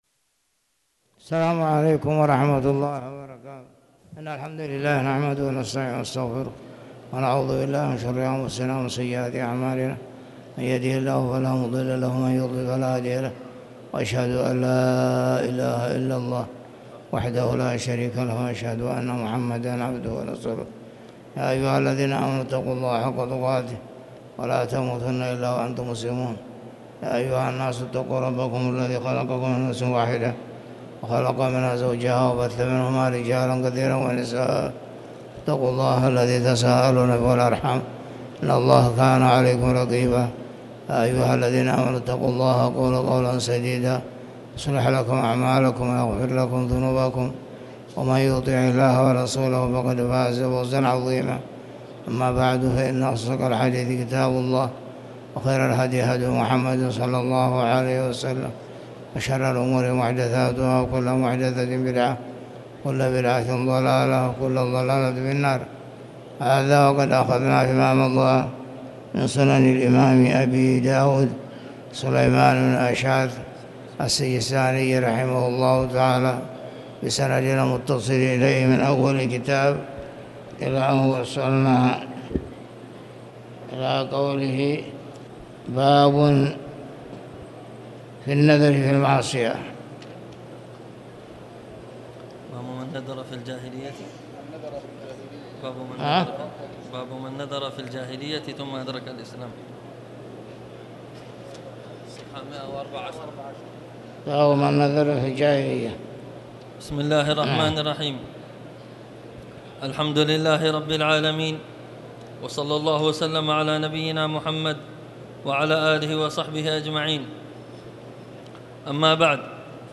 تاريخ النشر ٢٢ محرم ١٤٤٠ هـ المكان: المسجد الحرام الشيخ